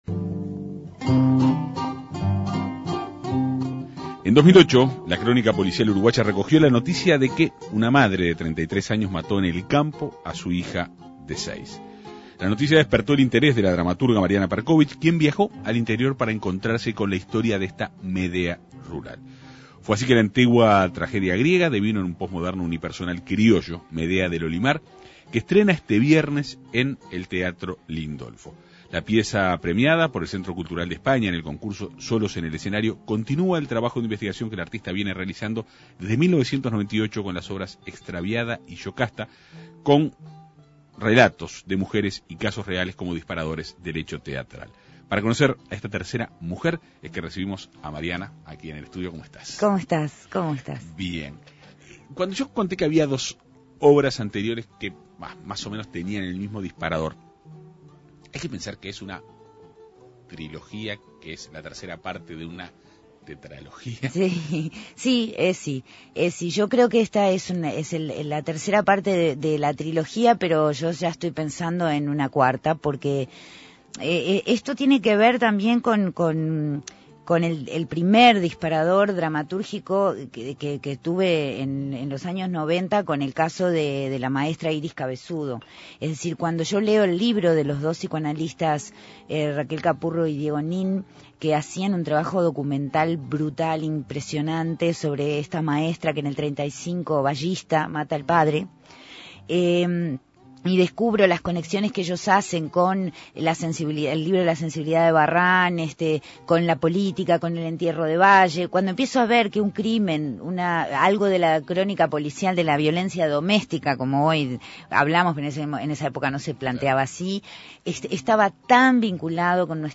Sobre la pieza teatral, dialogó en la Segunda Mañana de En Perspectiva.